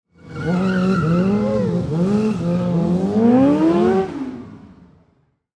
Motorcycle Tire Burn Drive Away